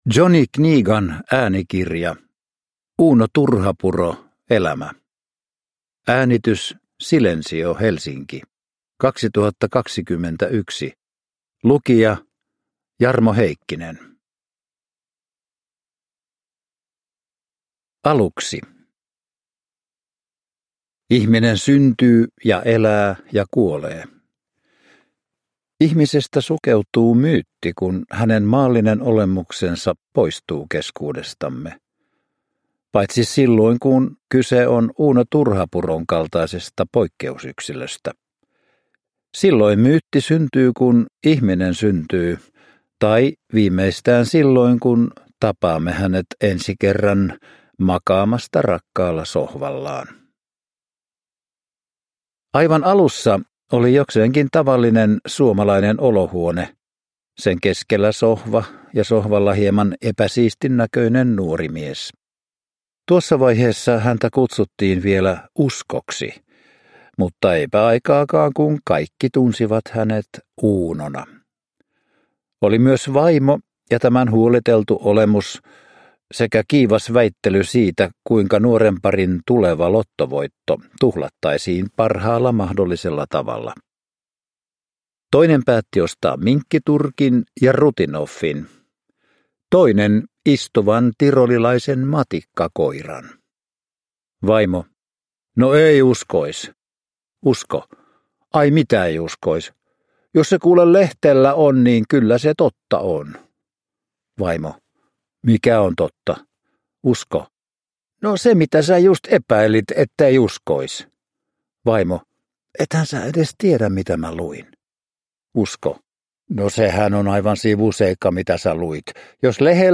Uuno Turhapuro – Ljudbok